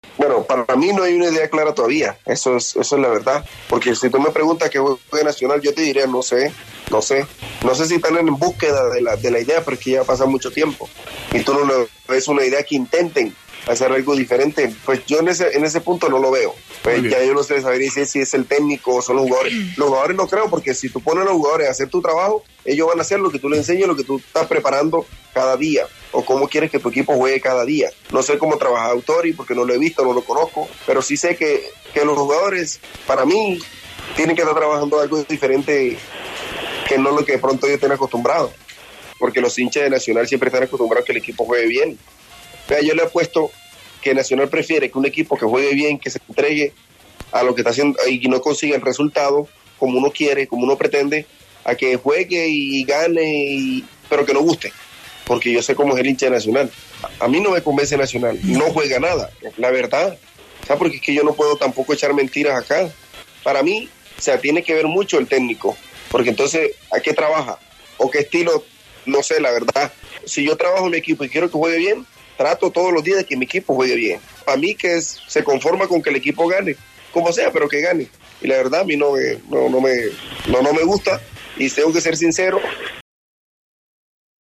(Farid Díaz, exjugador de Nacional, en El Alargue)